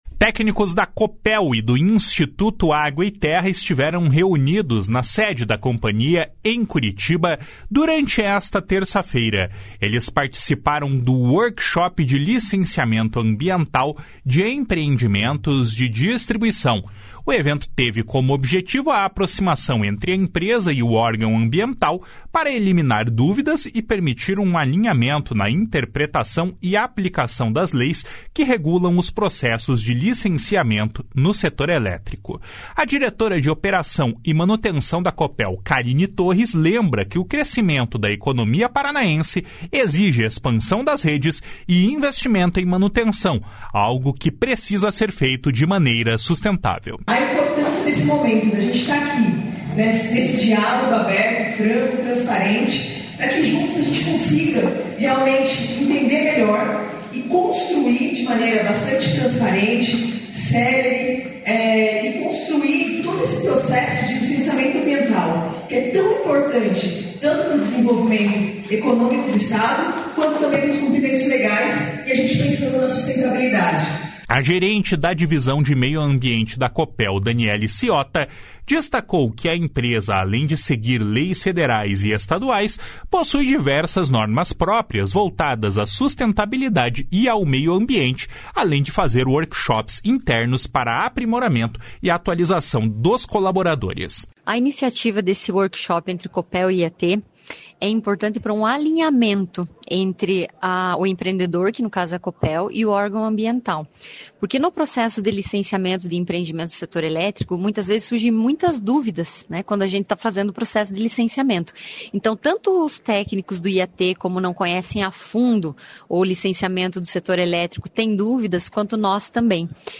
Um processo de licenciamento ambiental inclui checklists documental e do projeto, análise do estudo, vistoria e parecer conclusivo. (Repórter